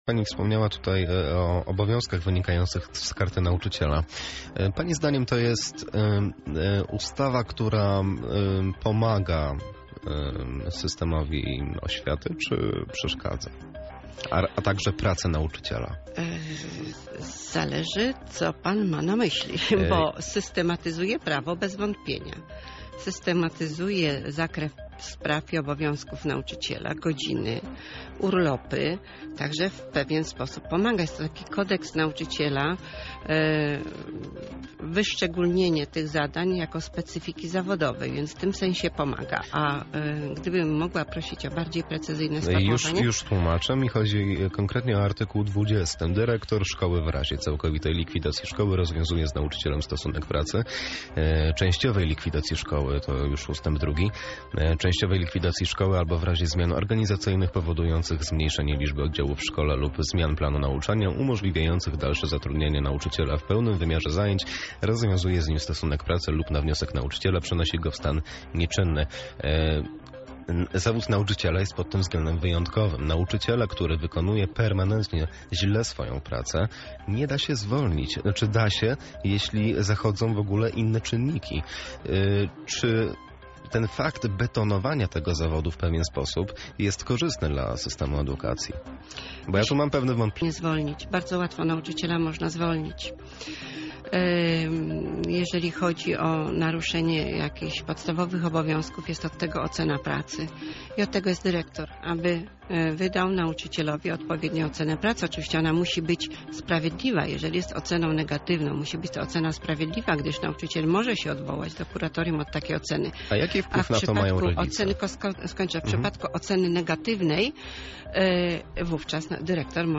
Pełna rozmowa dostępna poniżej: